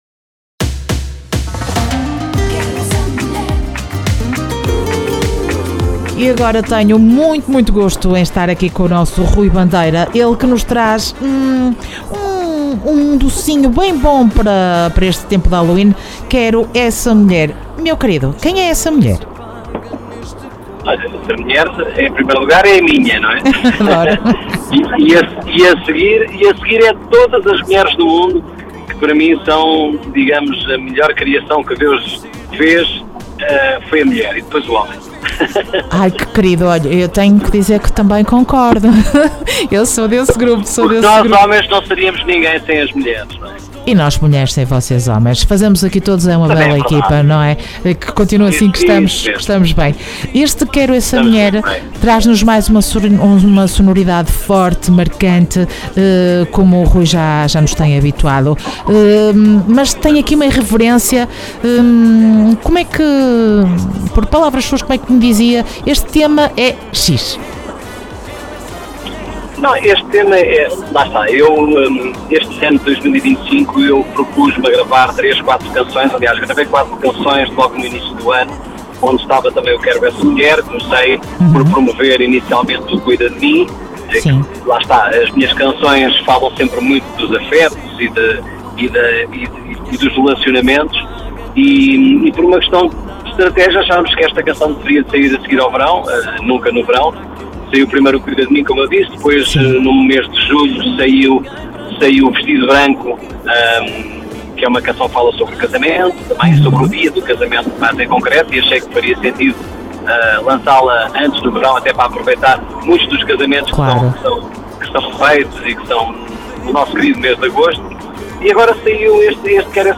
Entrevista Rui Bandeira dia 03 de Novembro.